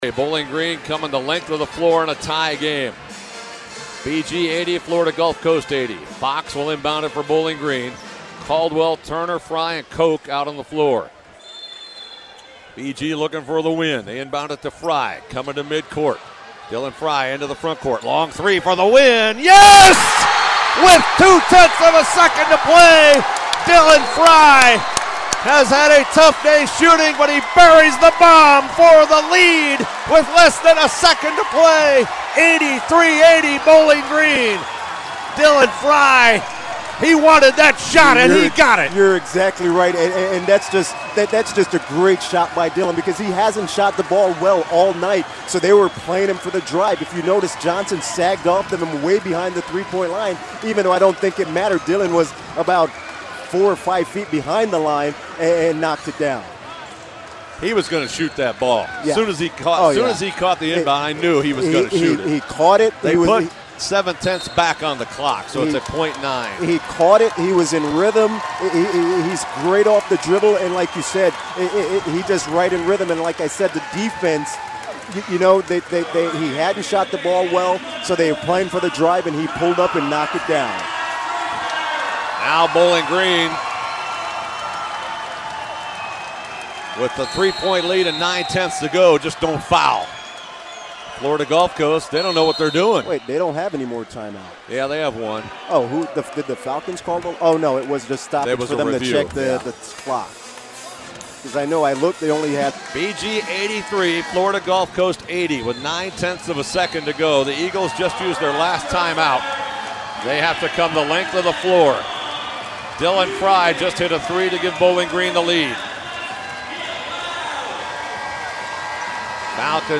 Radio Call